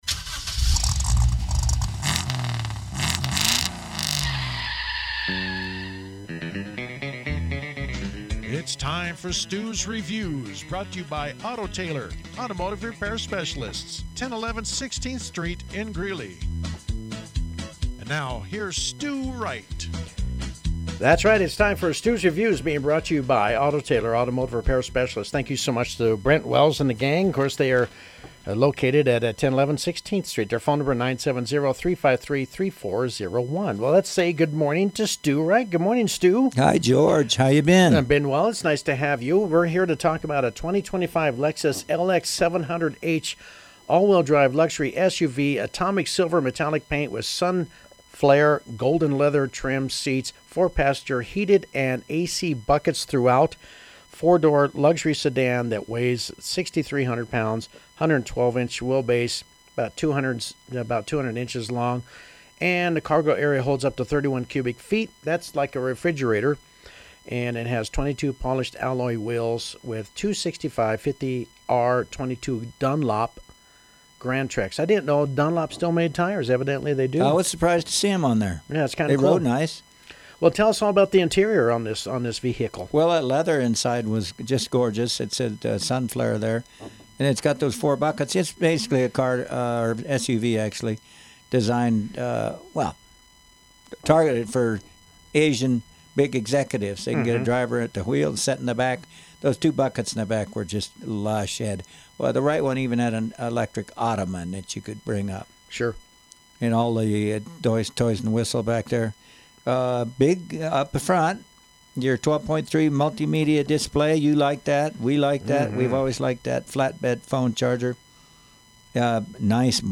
The hybrid Lexus was driven around Phoenix and reviewed later at the studios of Pirate Radio 104.7FM in Greeley